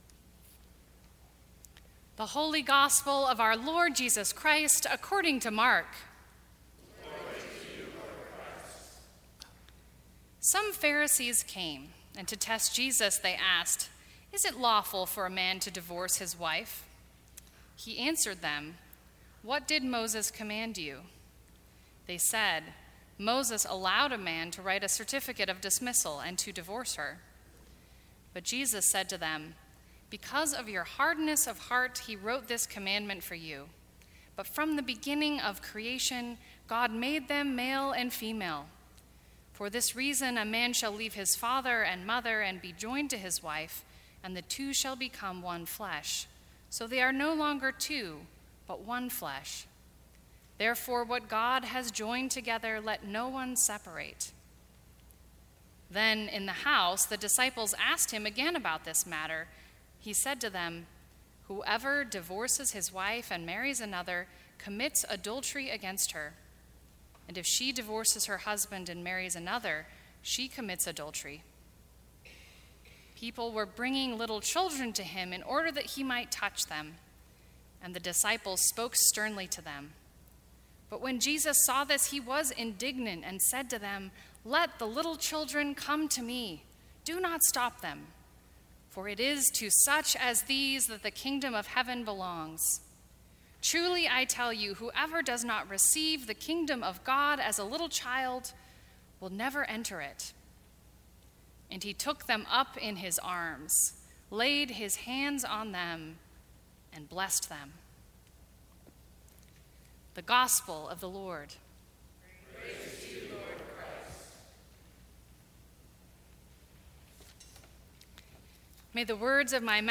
Sermons from St. Cross Episcopal Church To Be Like Children Oct 09 2018 | 00:16:24 1x 00:00 / 00:16:24 Subscribe Share Apple Podcasts Spotify Overcast RSS Feed Share Link Embed